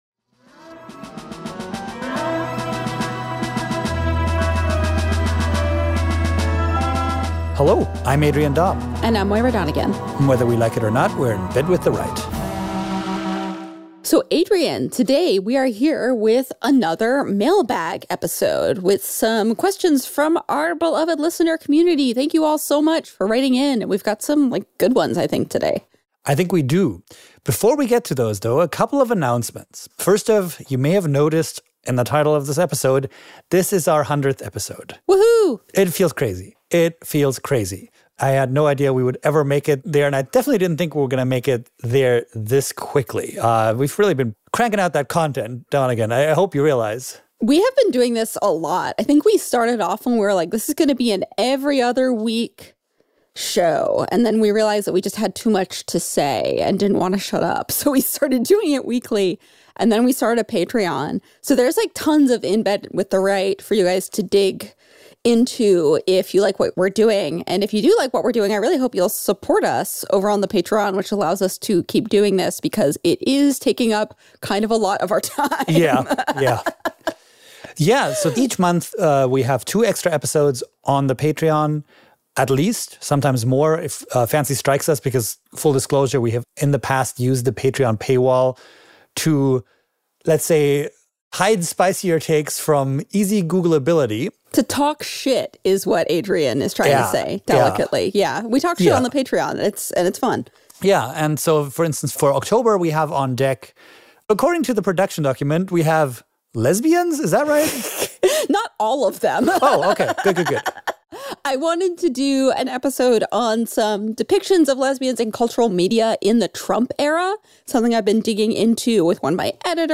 1 The terms deflection and containment should be banned - Interviews from Cisco's WebexOne 1:01:42